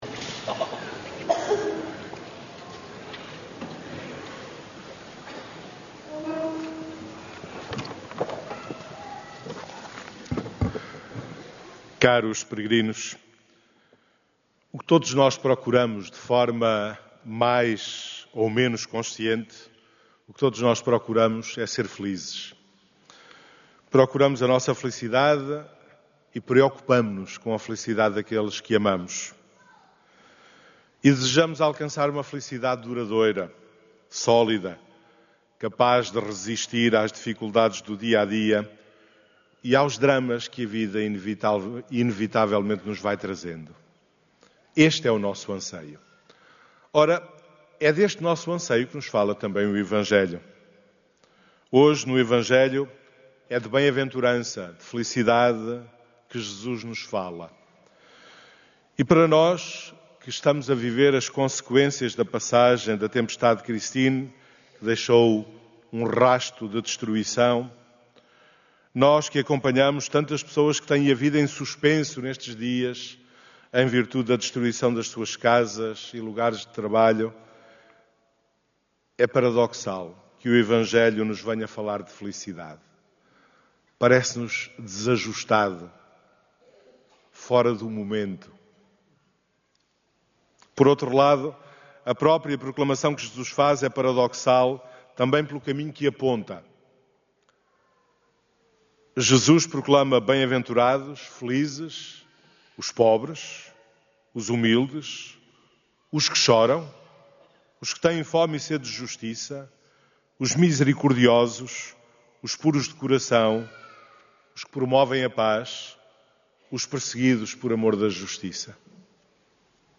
Áudio da homilia